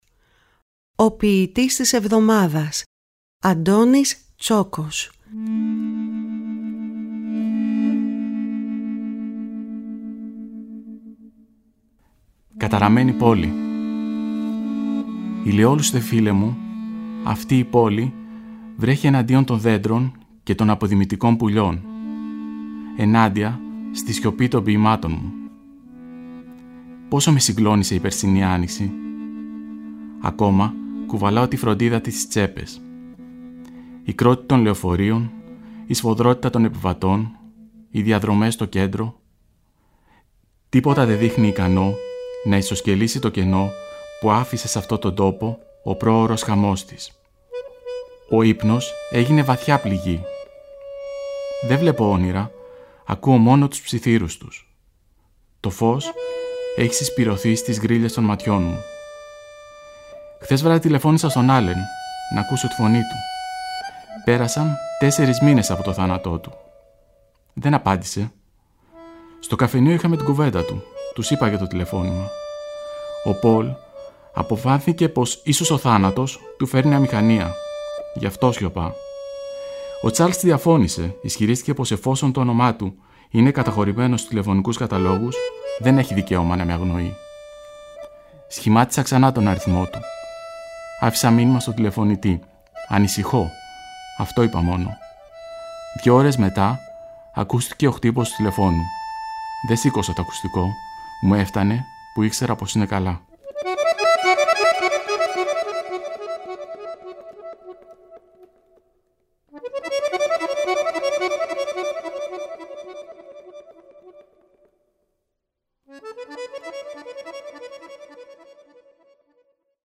Η ΦΩΝΗ ΤΗΣ ΕΛΛΑΔΑΣ, το ραδιόφωνο της ΕΡΤ που απευθύνεται στους Έλληνες όπου γης, με όχημα τη διάδοση, τη στήριξη, και την προβολή της ελληνικής γλώσσας και του ελληνικού πολιτισμού, εντάσσει και πάλι στο πρόγραμμά της τα αφιερωματικά δίλεπτα ποίησης με τίτλο «Ο ποιητής της εβδομάδας».
Οι ίδιοι οι ποιητές, καθώς και αγαπημένοι ηθοποιοί  επιμελούνται τις ραδιοφωνικές ερμηνείες. Παράλληλα τα ποιήματα «ντύνονται» με πρωτότυπη μουσική, που συνθέτουν και παίζουν στο στούντιο της Ελληνικής Ραδιοφωνίας οι μουσικοί της Ορχήστρας της ΕΡΤ, καθώς και με μουσικά κομμάτια αγαπημένων δημιουργών.
Μουσική επιμέλεια και σύνθεση: Μαρία Ρεμπούτσικα